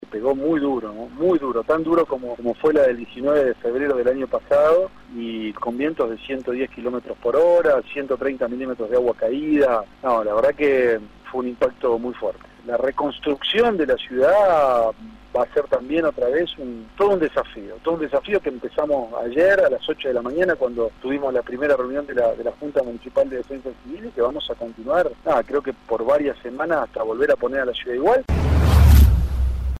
En diálogo con Aire de Santa Fe, el intendente de Rafaela, Luis Castellano destacó en primer lugar que “gracias a Dios no tenemos que lamentar víctimas, pero nos pegó muy duro. Nos hizo acordar a la tormenta del año pasado”.
Audio: Luis Castellano – Intendente de Rafaela